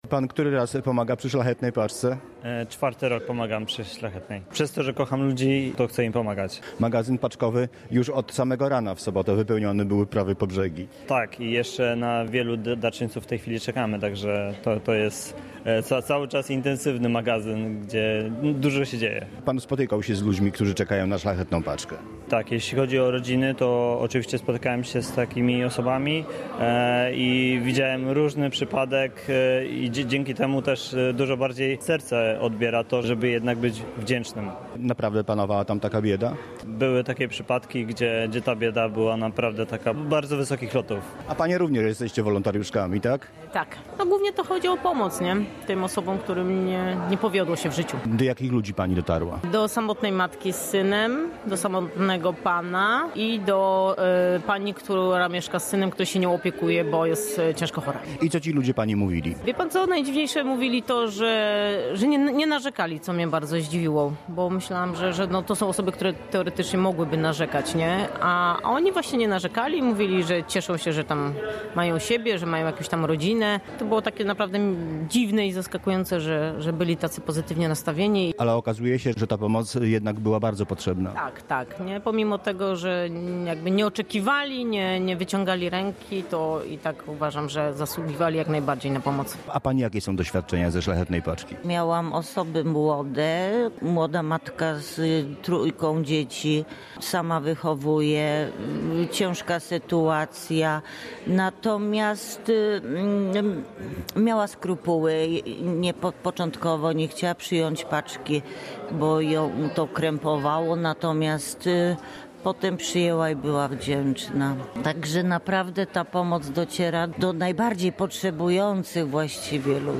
Cała akcja nie miałaby szans powodzenia, gdyby nie wolontariusze, którzy odwiedzali rodziny i kwalifikowali je do udziału w programie. Jak mówili w rozmowie z Polskim Radiem Rzeszów – wielu uczestników programu nie spodziewało się, że zostaną objęci pomocą, bo z trudem, ale jakoś sobie radzą.